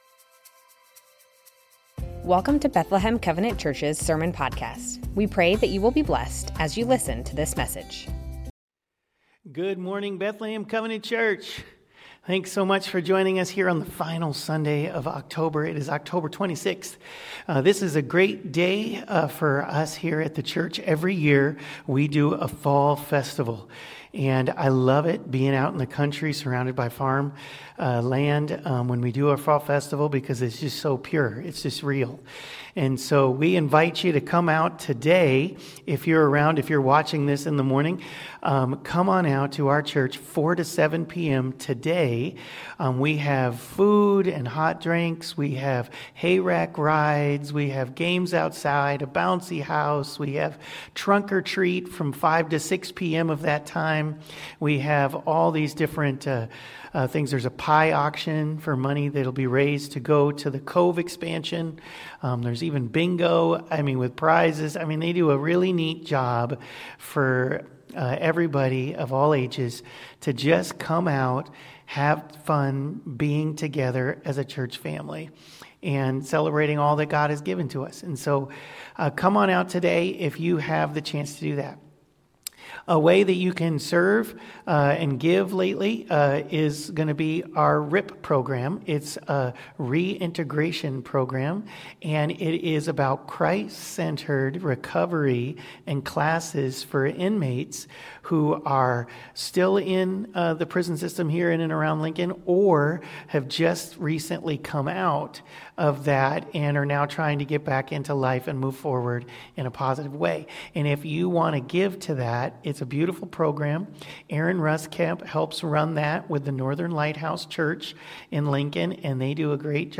Bethlehem Covenant Church Sermons The Names of God - El Olam Oct 26 2025 | 00:37:49 1x 00:00 / 00:37:49 Subscribe Share Spotify RSS Feed Share Link Embed